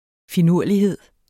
Udtale [ fiˈnuɐ̯ˀliˌheðˀ ]